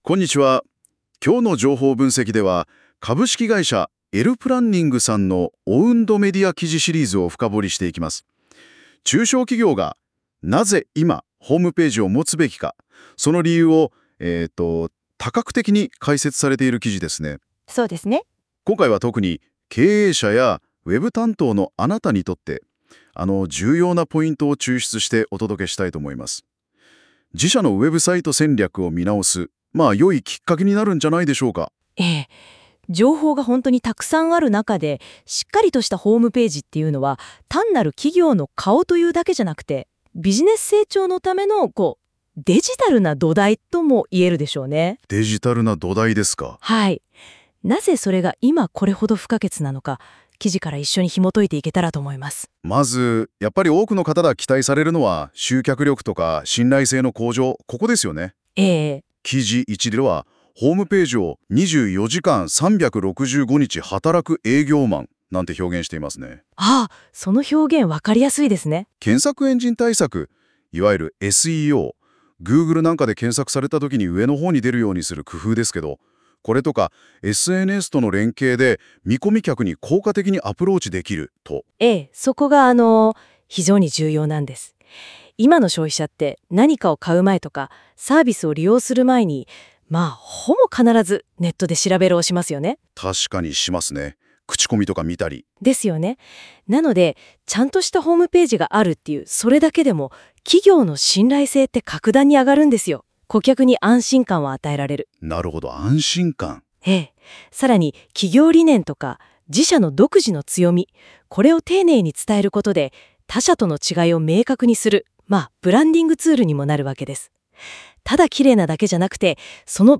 ”ラジオ風”に記事ダイジェストを解説！
音声ダイジェストは、AIサービスの音声機能を活用し、自動的に生成された要約内容です。